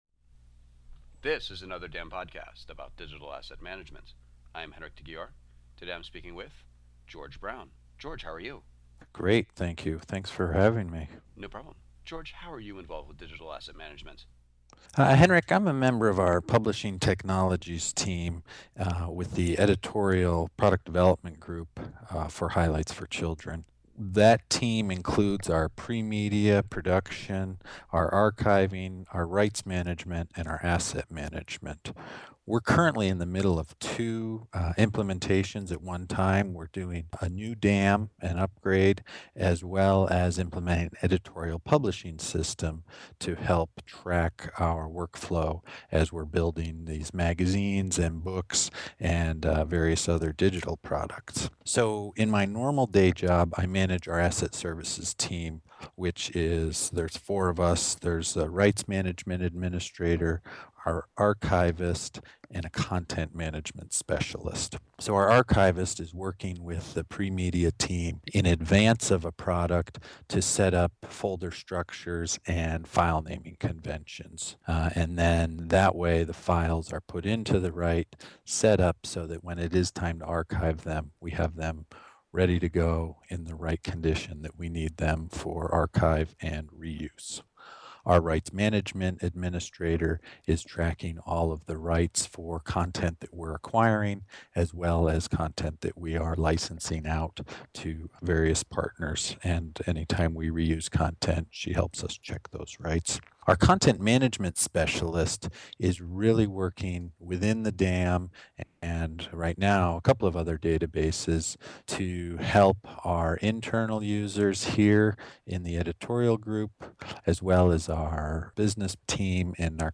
Here are the questions asked: